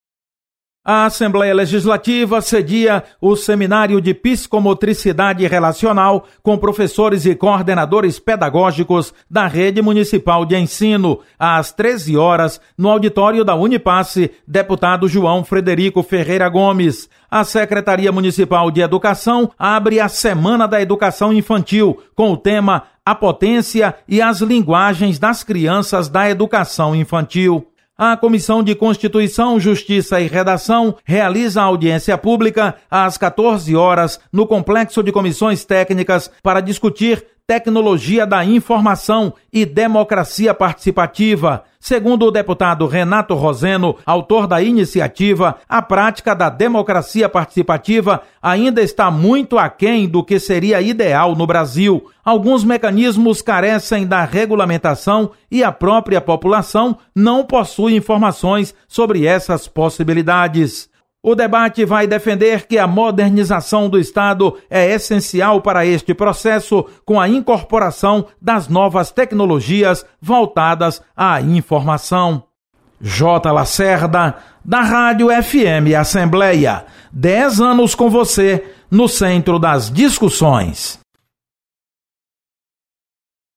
Acompanhe as atividades desta segunda-feira na Assembleia Legislativa. Repórter